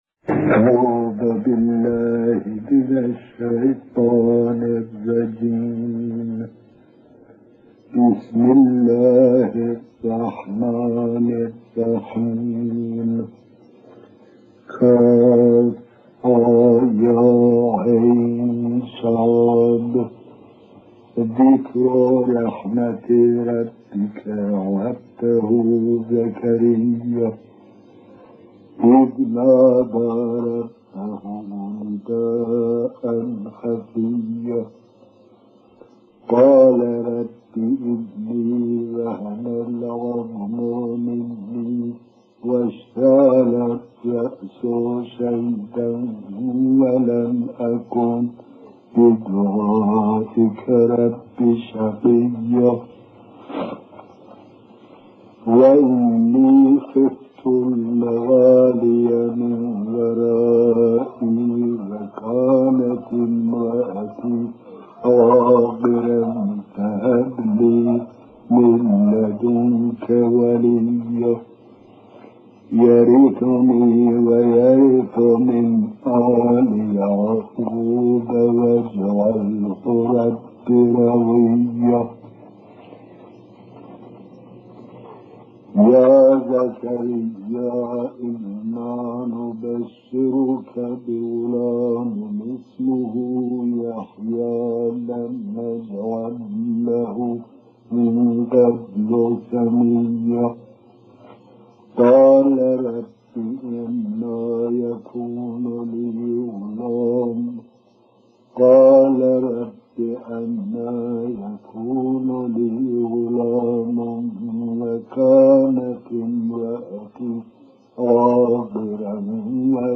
علامه طبابایی.تلاوت سوره ی مریم.mp3
علامه-طبابایی.تلاوت-سوره-ی-مریم.mp3